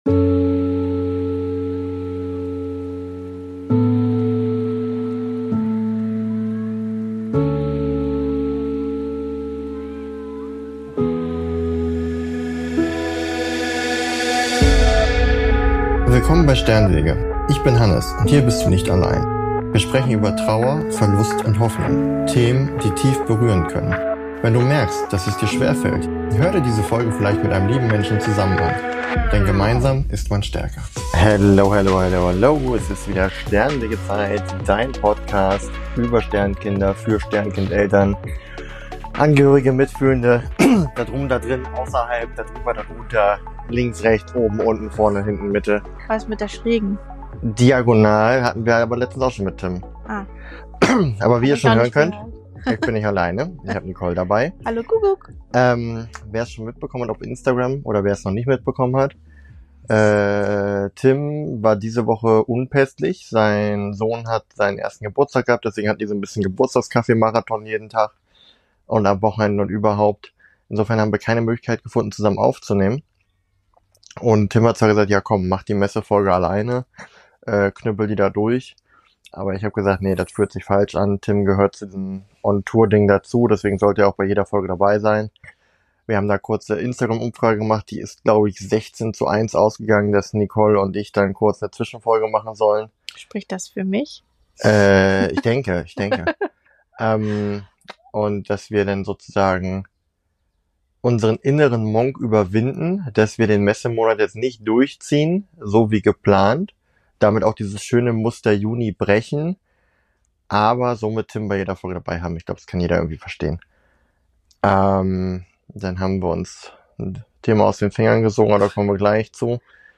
Also haben wir das Mikro im Vorzelt aufgebaut und einfach mal gesprochen: über Elternzeit, Alltag, Wickelräume und vor allem über die Frage, wie aus tiefem Schmerz etwas Gutes entstehen kann.